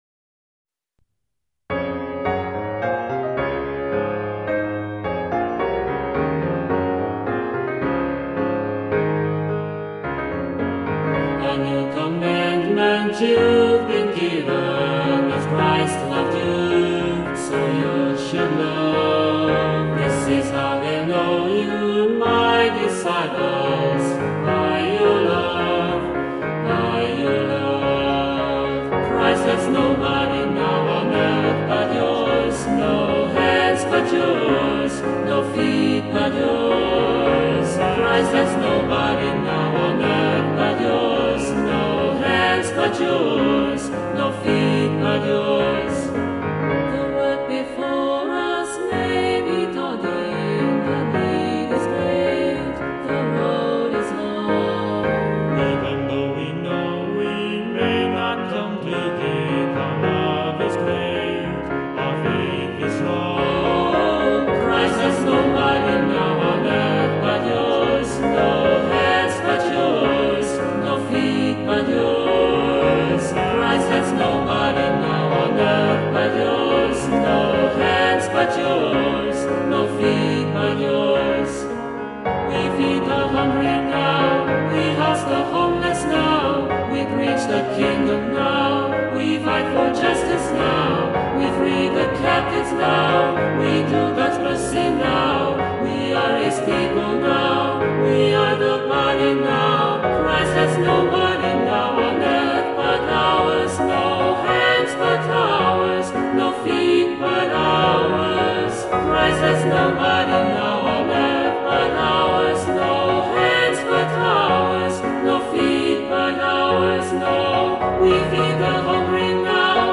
SATB, SAT, SSA, TBB